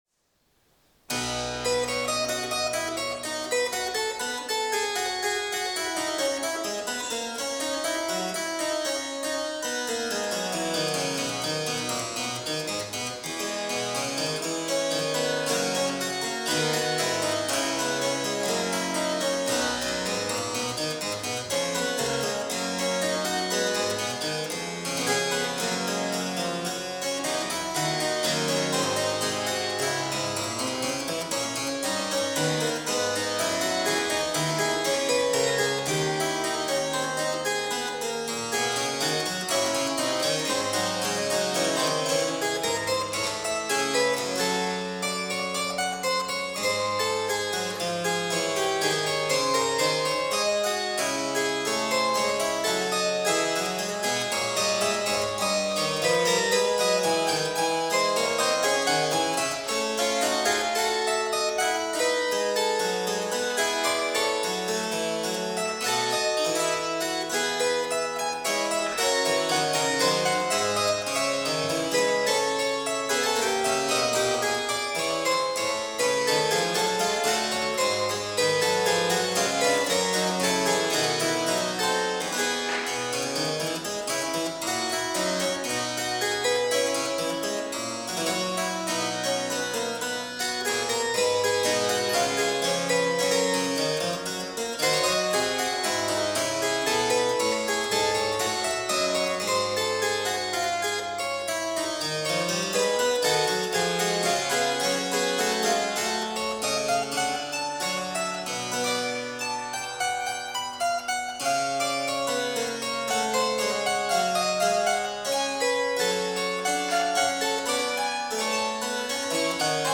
Playing Bach (J.S + W.F.?) on the Harpsichord
This version still lacks the characteristic demisemiquaver flourishes found in the later version. But it explores chromatic voice-leadings (bars 9, 24) that we do not see in other versions.
My rehearsal recording in the Sommersaal, Bach-Archiv Leipzig on 5 May 2005